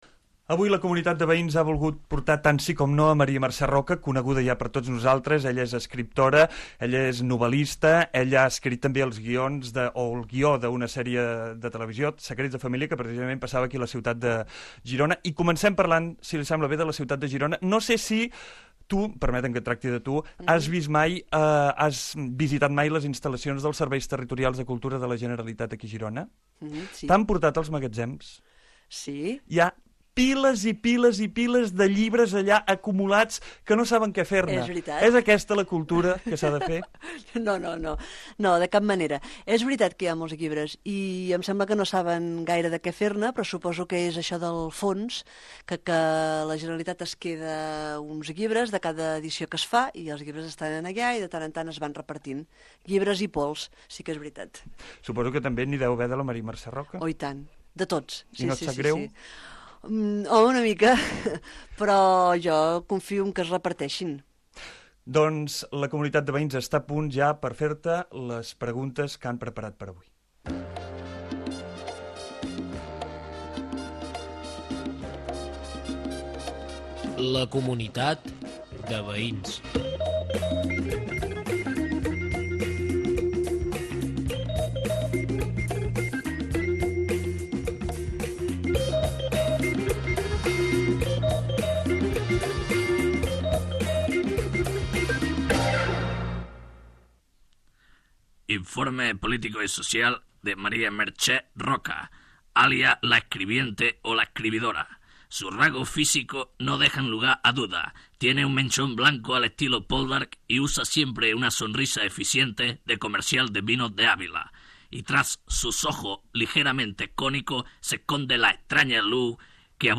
Conversa inicial, careta del programa, perfil de la invitada i entrevista a l'escriptora Maria Mercè Roca. Intervenen els personatges Artemio Gargallo, Jep Tarrés i Ramon Juncosa
Entreteniment